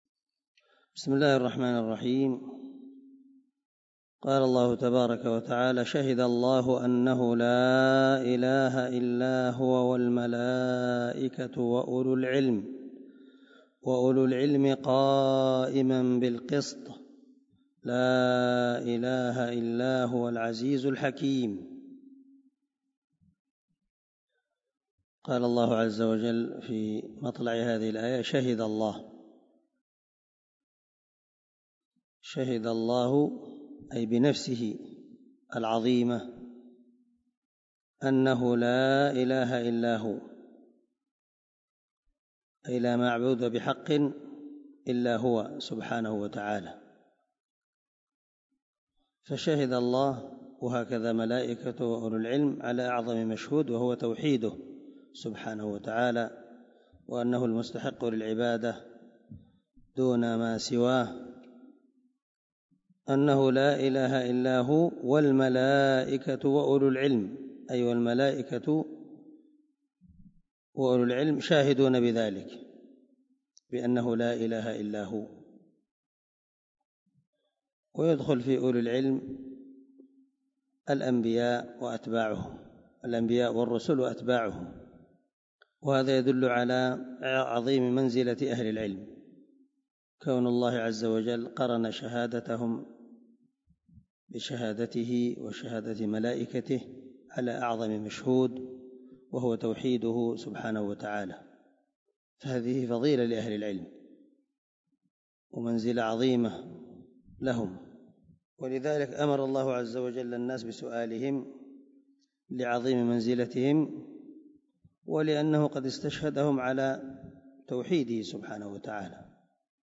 161الدرس 6 تفسير آية ( 18 – 19 ) من سورة آل عمران من تفسير القران الكريم مع قراءة لتفسير السعدي
دار الحديث- المَحاوِلة- الصبيحة.